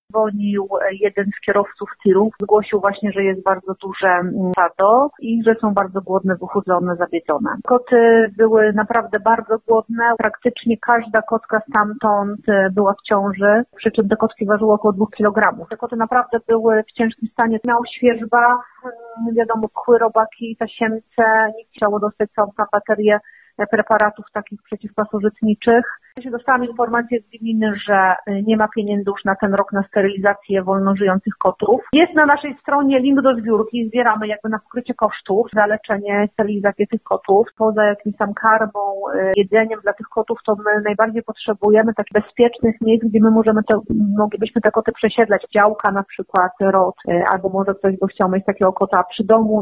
O szczegółach mówi wicemarszałek województwa wielkopolskiego, Krzysztof Grabowski: